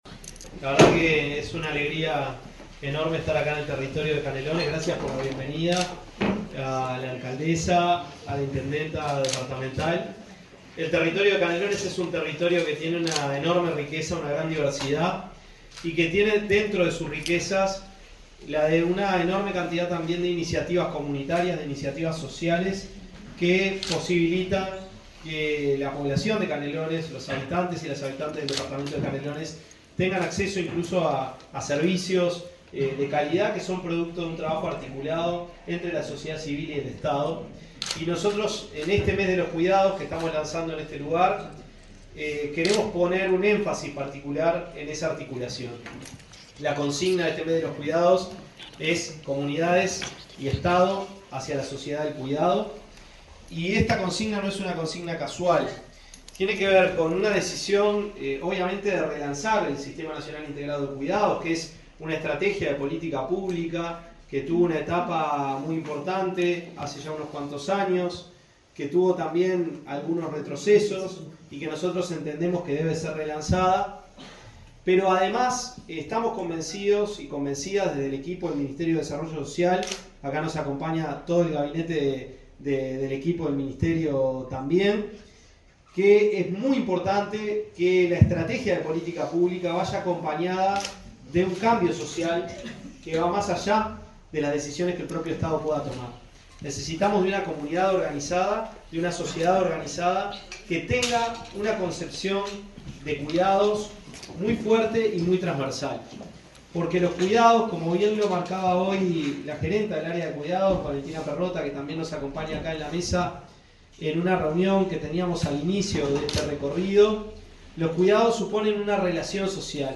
Conferencia del ministro de Desarrollo Social, Gonzalo Civila
El ministro de Desarrollo Social, Gonzalo Civila, realizó una conferencia de prensa en Canelones, luego de visitar tres modelos diferentes del Sistema